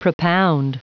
Prononciation du mot propound en anglais (fichier audio)
Prononciation du mot : propound